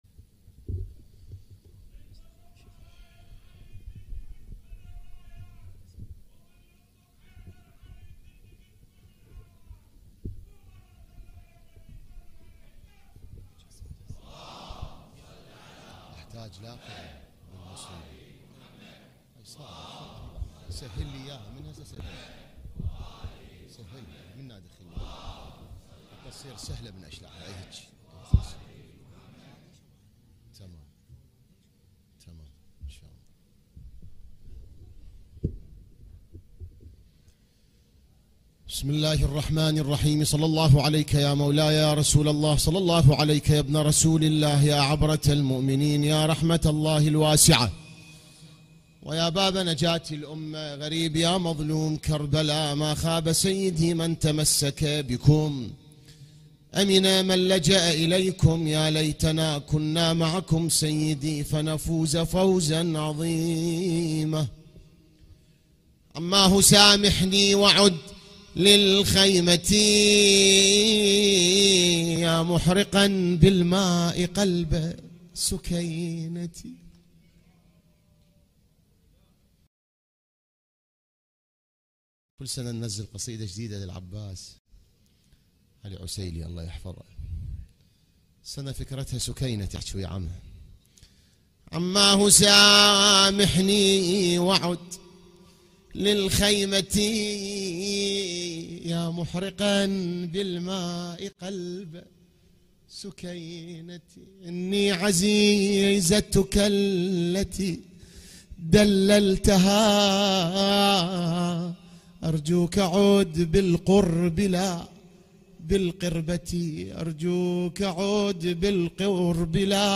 ليلة ٧ محرم ١٤٤٦هـ || هيئة الزهراء للعزاء المركزي في النجف الاشرف
الان-مباشرة-ليلة-٧-محرم-١٤٤٦هـ-هيئة-الزهراء-للعزاء-المركزي-في-النجف-الاشرف.mp3